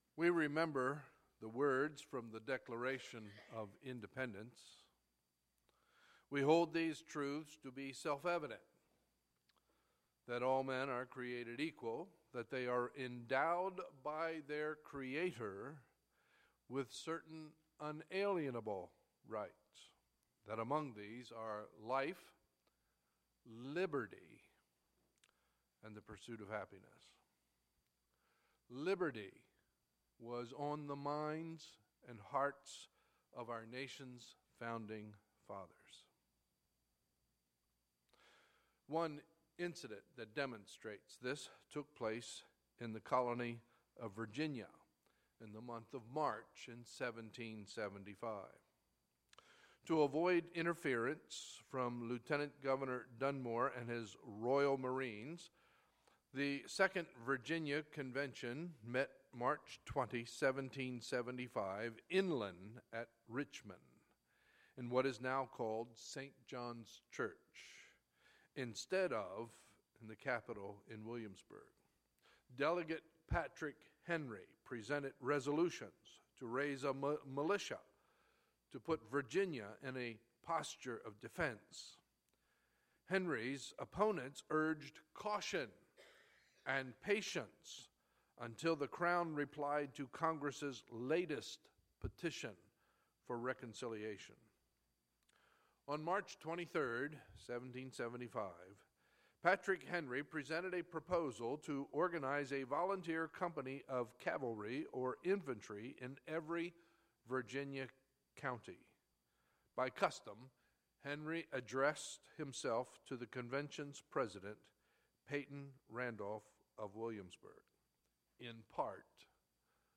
Sunday, July 3, 2016 – Sunday Morning Service